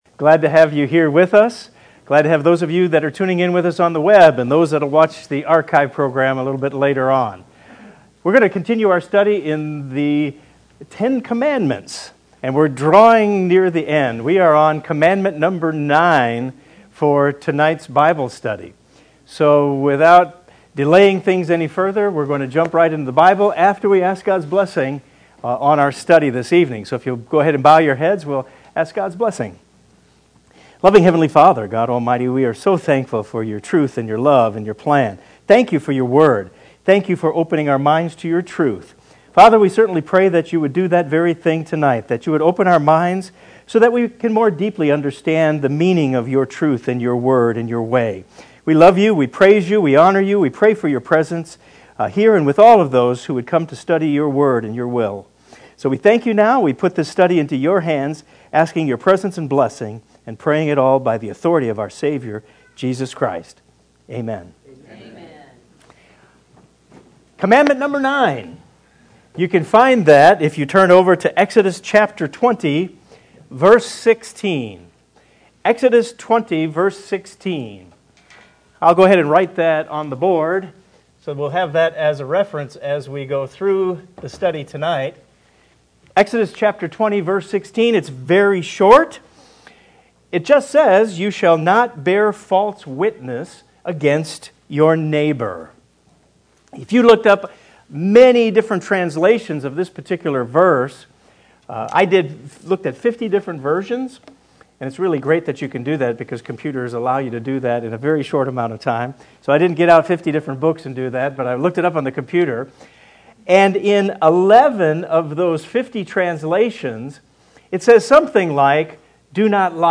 This is the ninth part in the Beyond Today Bible study series: The Ten Commandments.